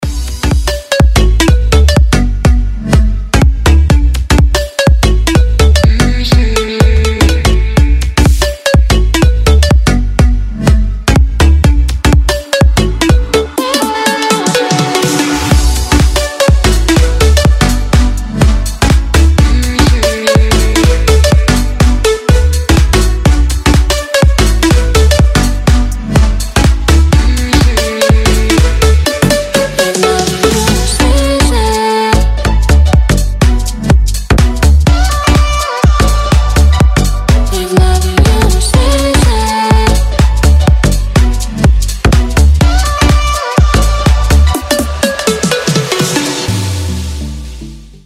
• Качество: 320, Stereo
dance
club
приятные
Стиль: Future House